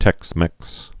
(tĕksmĕks)